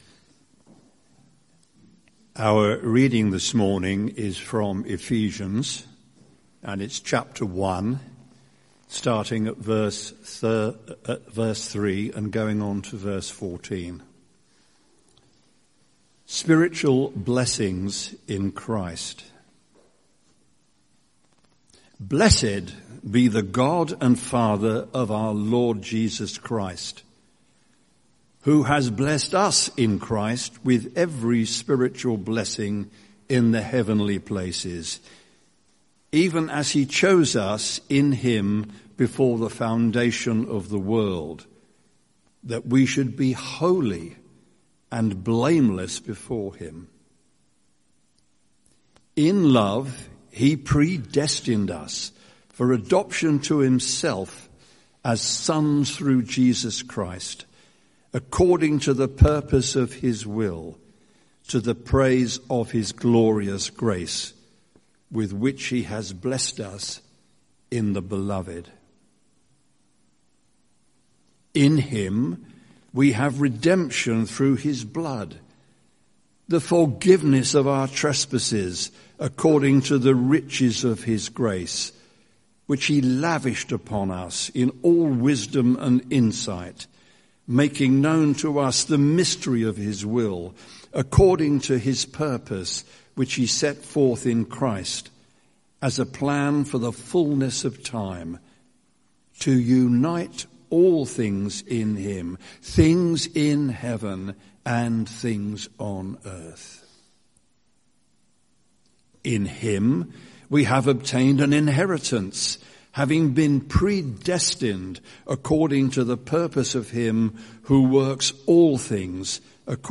Sermon Series: The 5 Solas of the Reformation